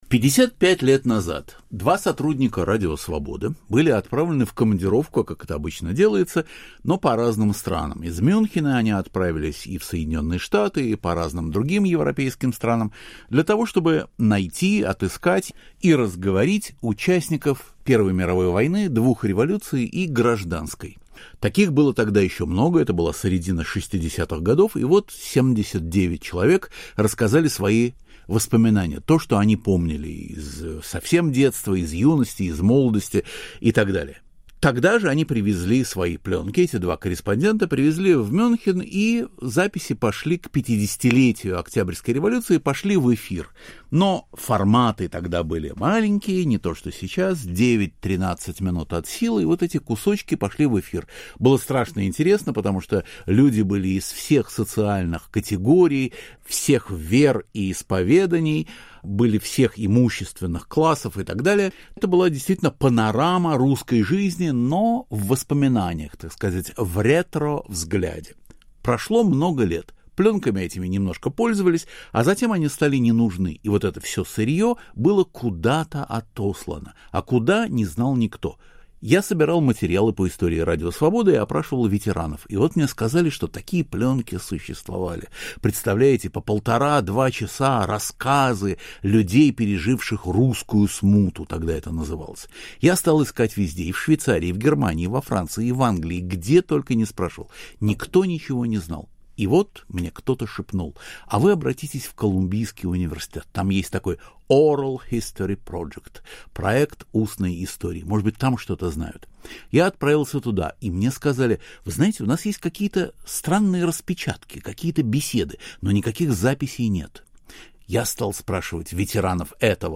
Интервью с редактором книги "Русское лихолетье" Иваном Толстым